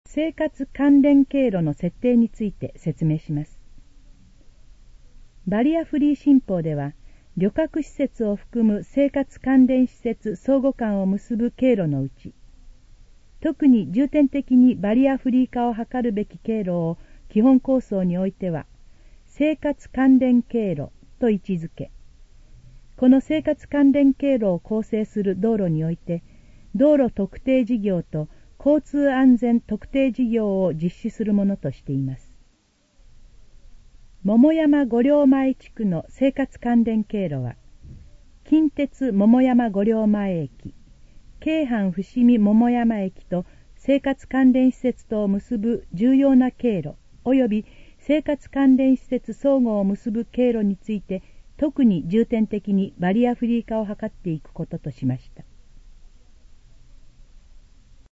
以下の項目の要約を音声で読み上げます。
ナレーション再生 約227KB